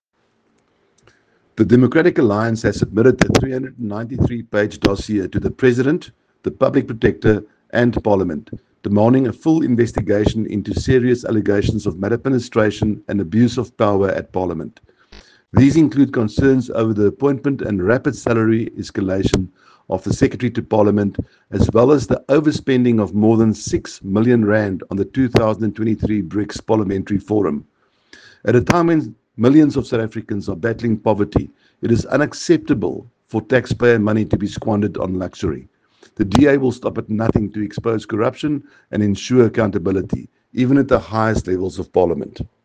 soundbite by Rikus Badenhorst MP.